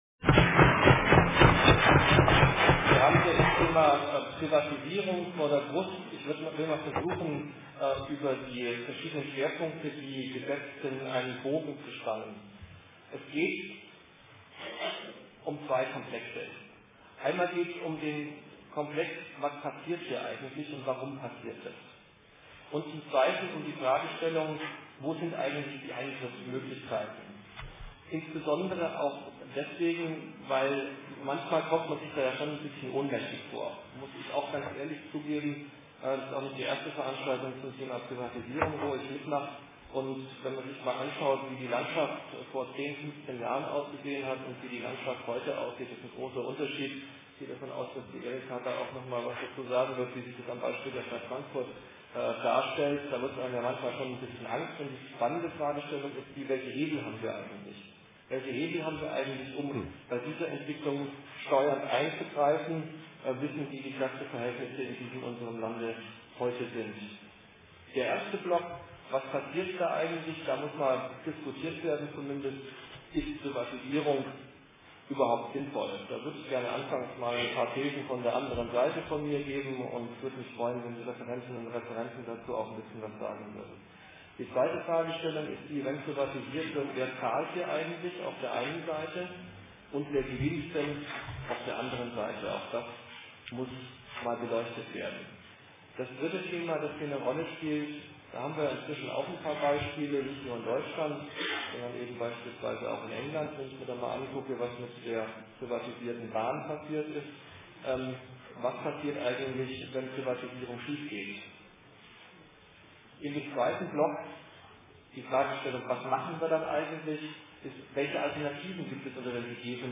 Attac-Frankfurt, das Bündnis gegen Privatisierung, IG Metall Ffm, GEW BV-Ffm und ver.di Hessen FB Kommunen hatten ins Gewerkschaftshaus eingeladen.
Moderation
Teil 1: Vorträge (komplett)